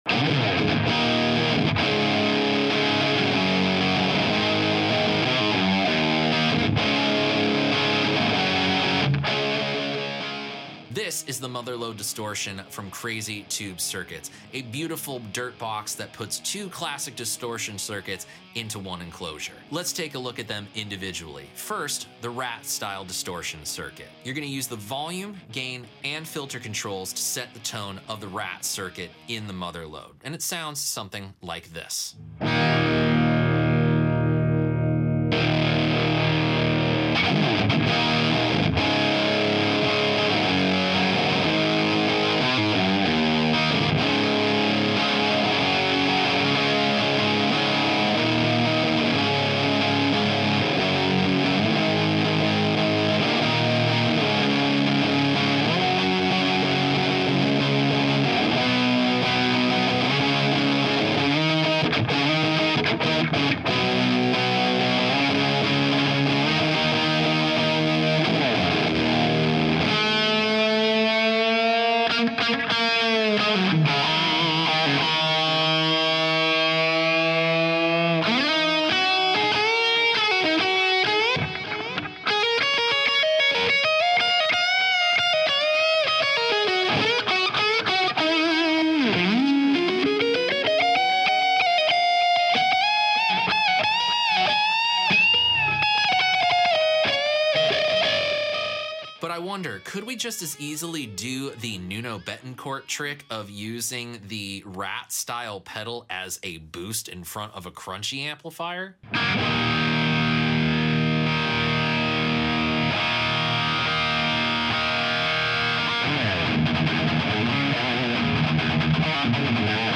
Hit the Motherload 🏆 Two classic distortion pedals in one pedal! Let’s hear the RAT distortion in the Crazy Tube Circuits Motherload distortion 🎛